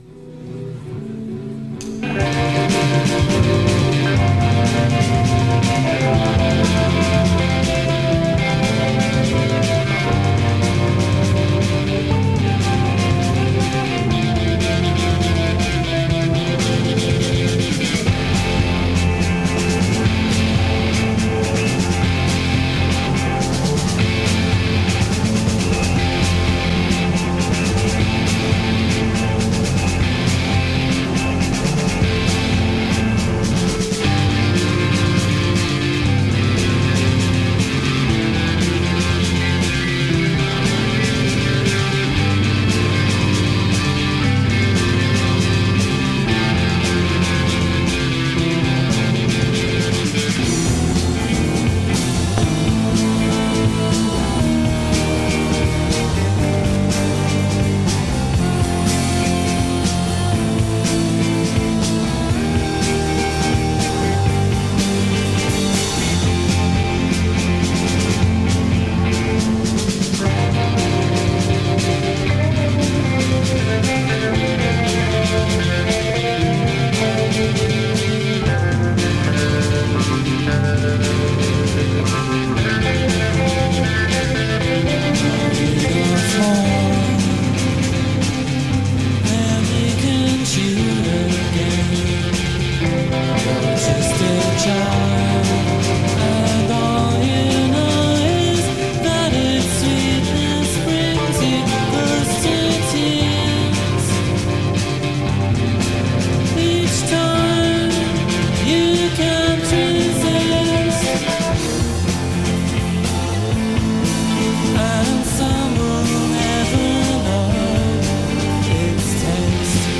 - Recorded June 26, 1991 - Brixton Academy, London.
Recorded in concert at Brixton Academy on June 26, 1991.
jangly indie pop band
ethereal, choirboy-like vocals
dark atmospheric and noisy pop tunes